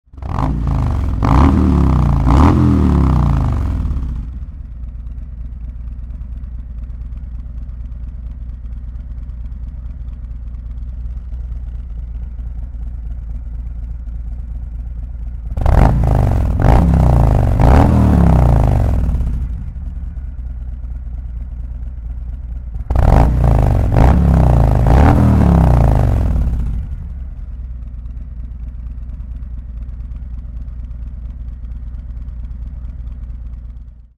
Silencieux Slip-On Mk 45
Utilisant à la fois des garnitures en acier inoxydable et en fibre de verre, ces silencieux créent un son de performance profond et une puissance améliorée.